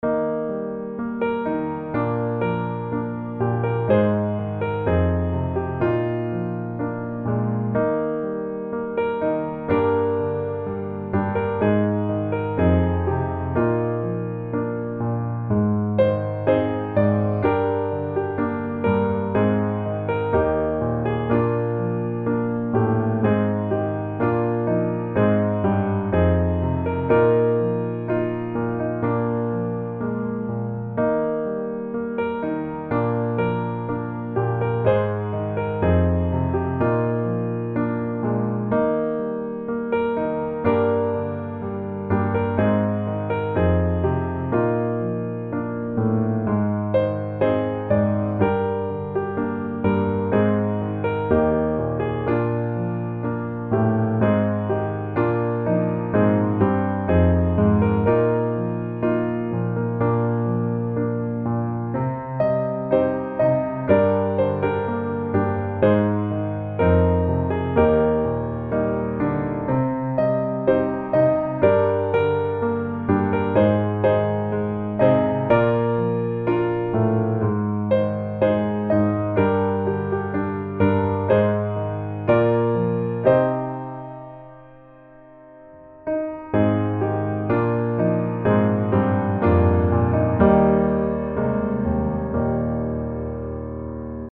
降E大調